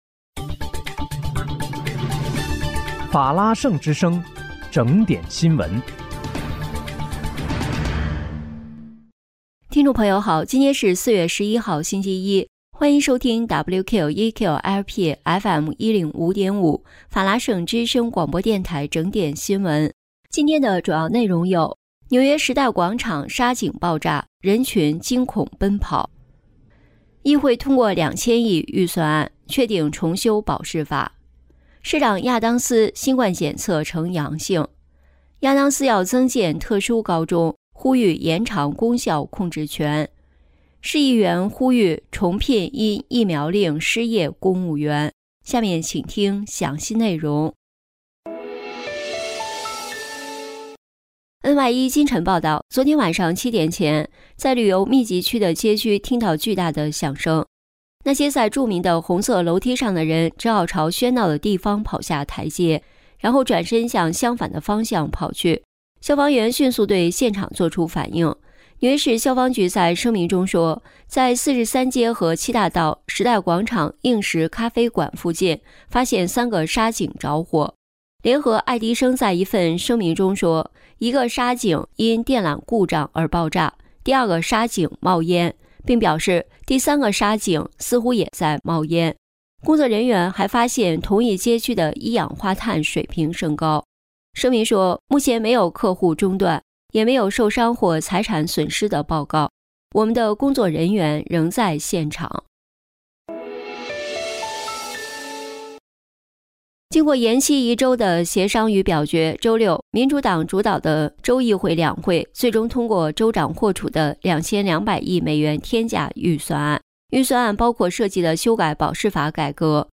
4月11日（星期一）纽约整点新闻